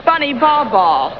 Bunny "Ball Ball" Mayflower's voice (Woof?) is provided by Frank Welker.